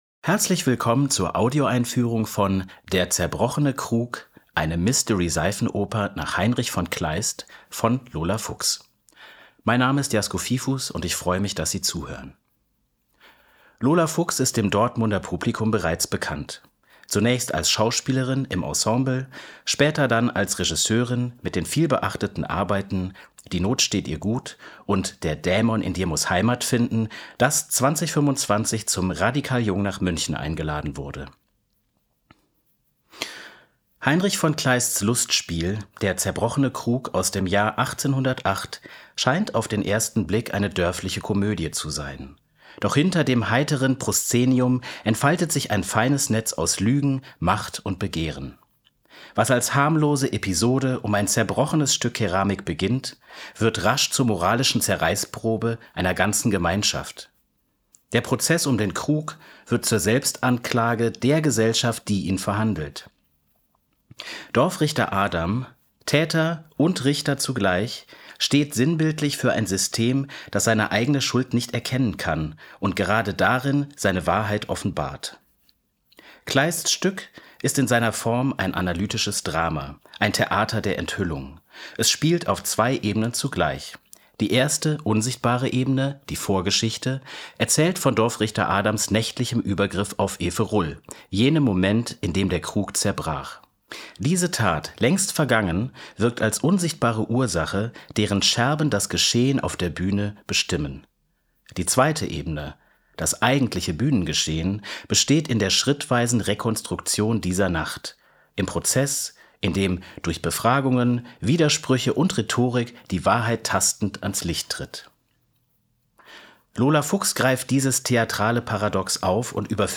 tdo_Audioeinfuehrung_Krug.mp3